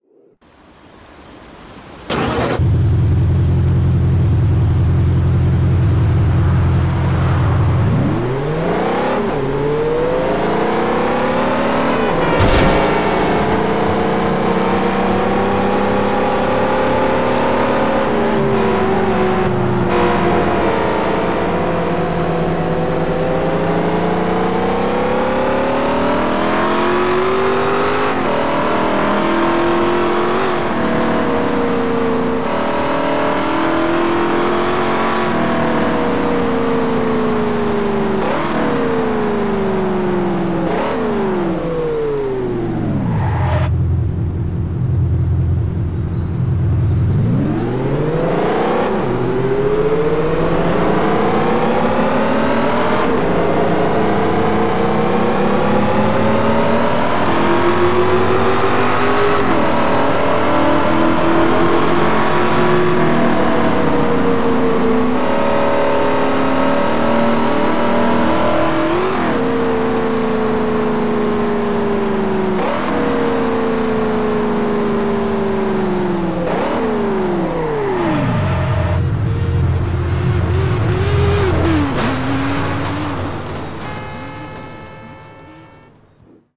- BMW M3 [E92] [EDM V8 / Supercharged]